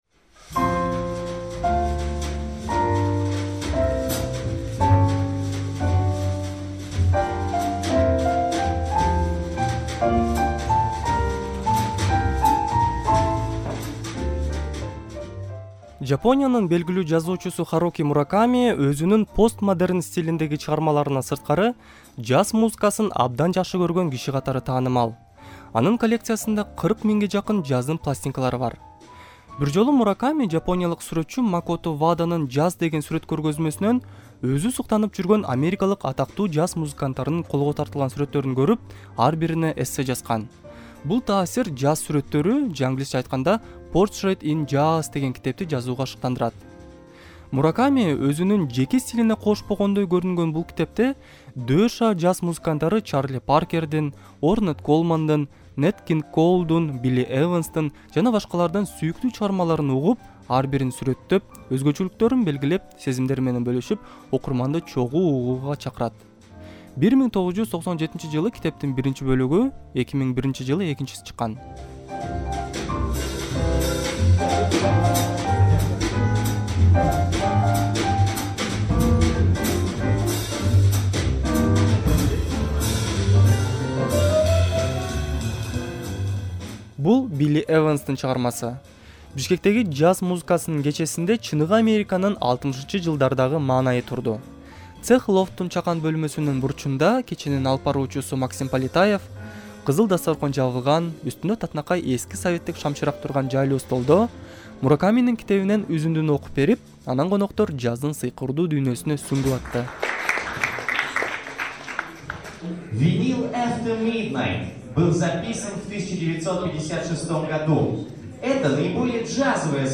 Жаз кечесинен репортаж